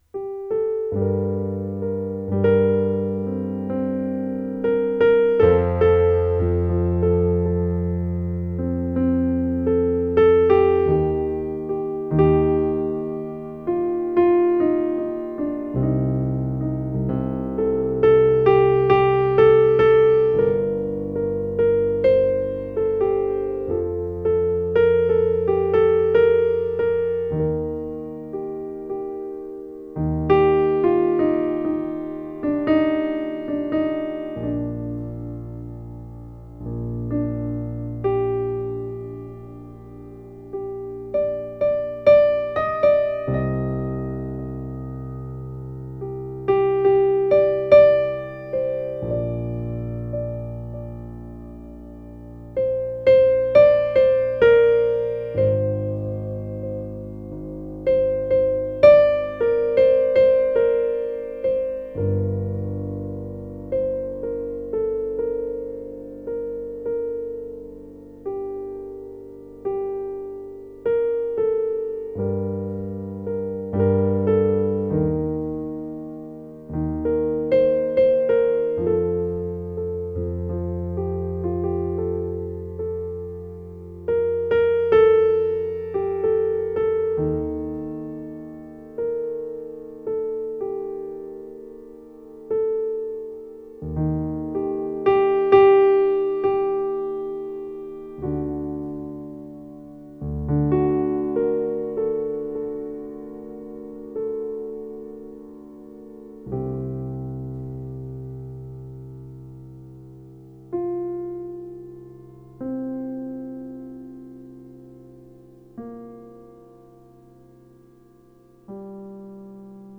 PIANO H-P (34)